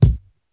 INSKICK09 -L.wav